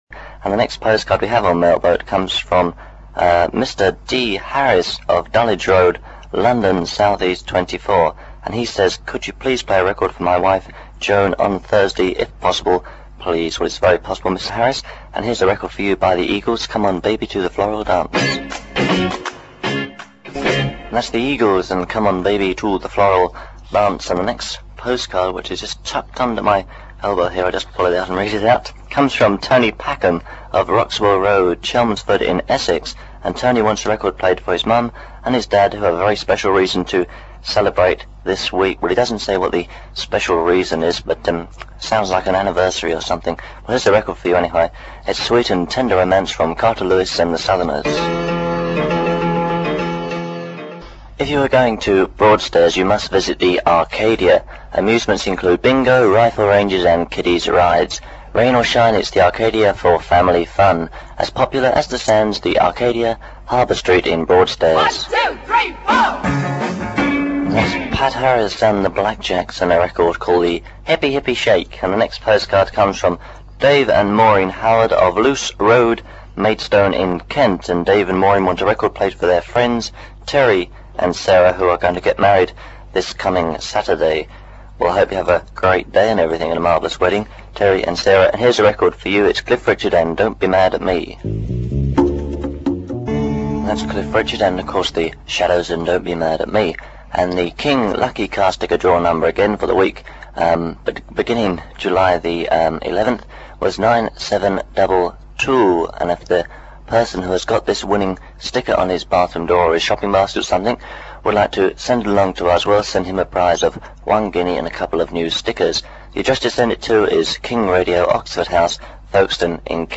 For the first time you can hear King Radio as it sounded in the studio.
the daily request show